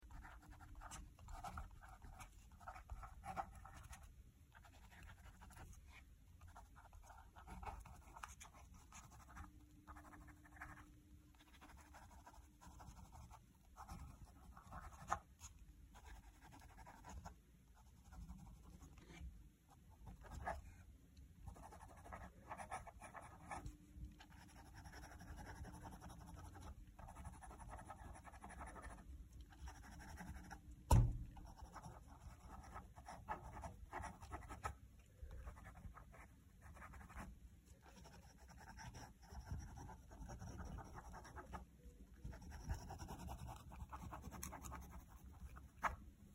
На этой странице собраны звуки пишущей ручки: от легкого постукивания по бумаге до равномерного скольжения стержня.
Звук шариковой ручки скользящей по бумаге